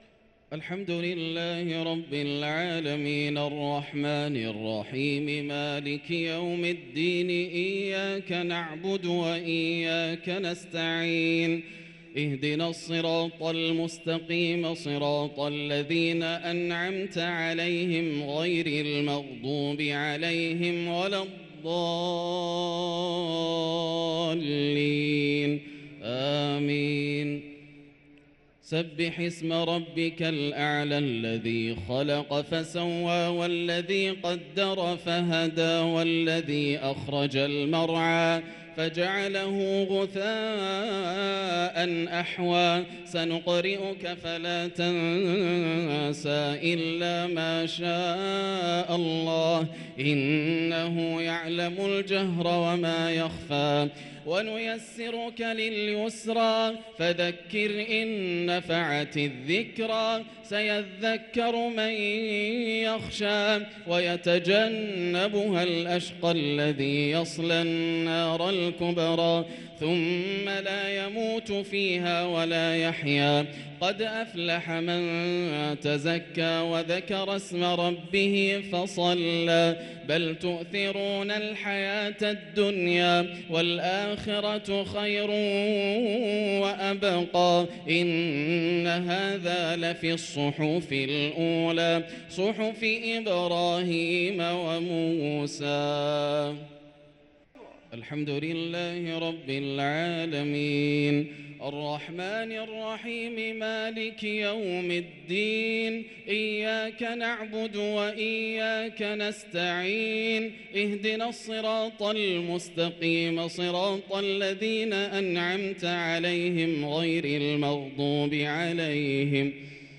الشفع و الوتر ليلة 3 رمضان 1444هـ | Witr 3 st night Ramadan 1444H > تراويح الحرم المكي عام 1444 🕋 > التراويح - تلاوات الحرمين